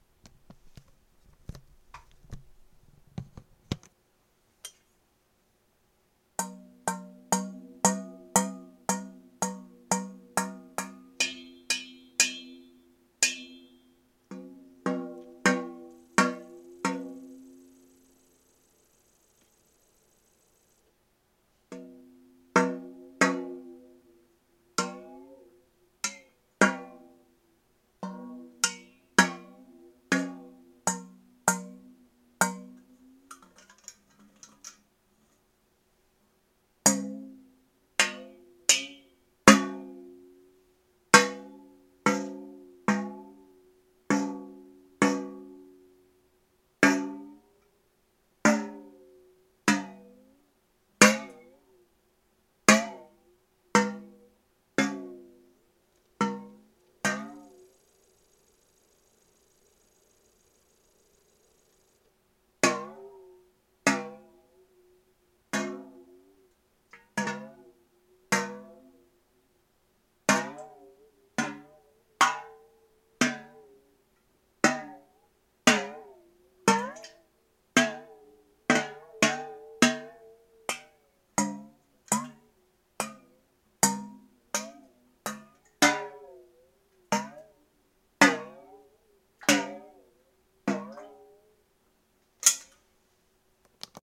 kettle-watergong4
bonk clang domestic gong kettle kitchen tap water sound effect free sound royalty free Nature